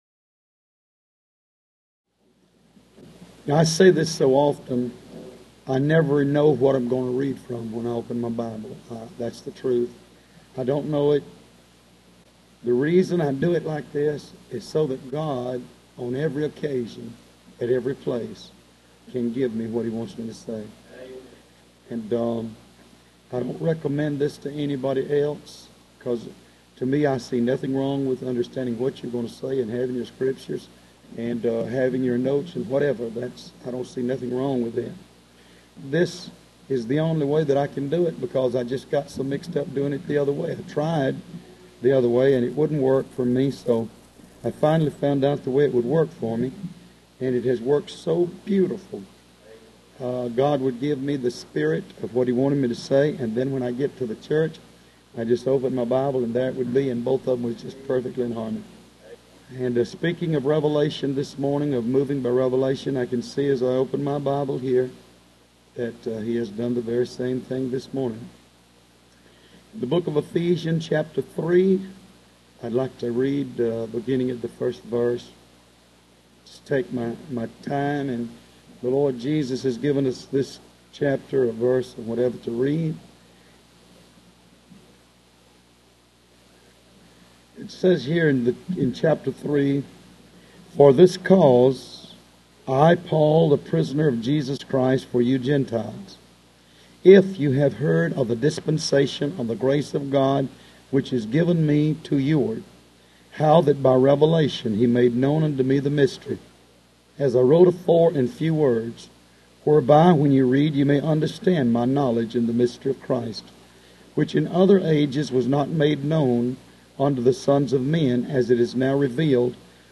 The Unity Of The Faith \n Home Sermons HOLINESS UNTO THE LORD The Unity Of The Faith Location